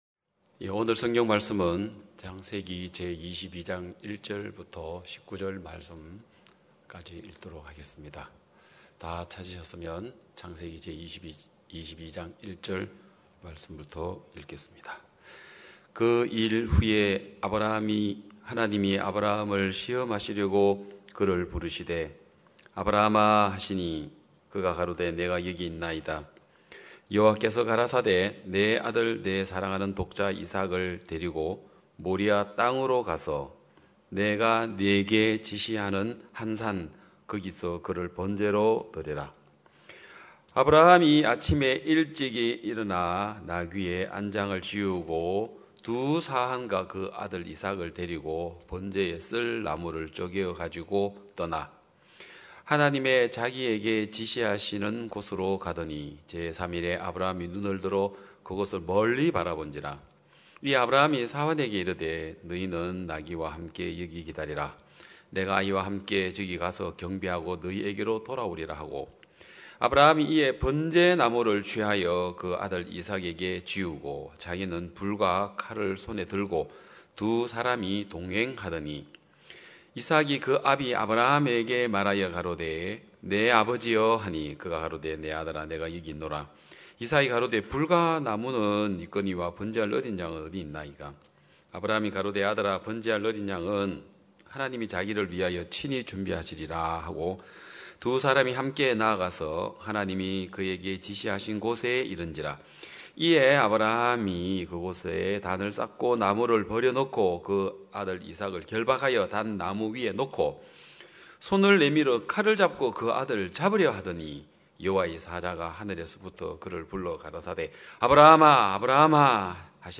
2021년 9월 12일 기쁜소식양천교회 주일오전예배
성도들이 모두 교회에 모여 말씀을 듣는 주일 예배의 설교는, 한 주간 우리 마음을 채웠던 생각을 내려두고 하나님의 말씀으로 가득 채우는 시간입니다.